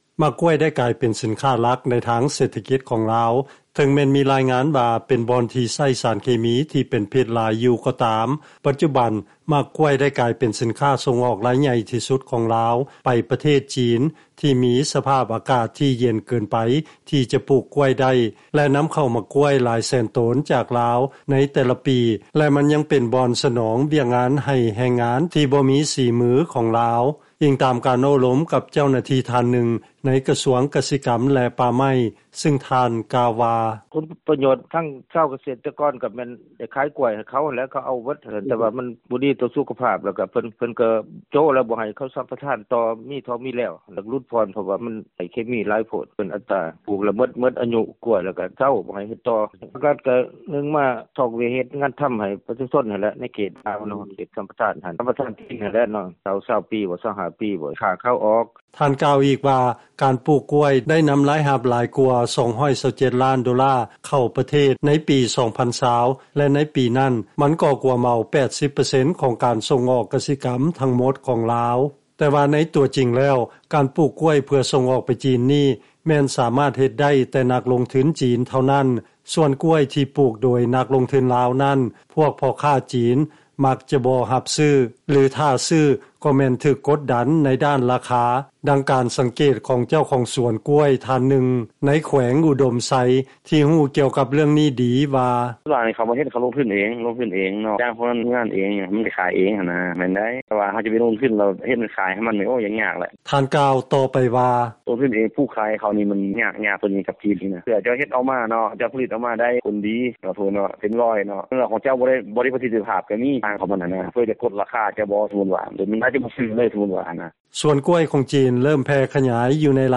ຟັງລາຍງານ ການລົງທຶນປູກກ້ວຍໃນລາວ ສ່ວນຫຼາຍແມ່ນຊາວຈີນ ມາເຮັດເອງ ໂດຍຈ້າງແຮງງານລາວ ສ່ວນຊາວກະເສດຕະກອນລາວ ຢາກລົງທຶນເອງ ແມ່ນຍາກຫຼາຍ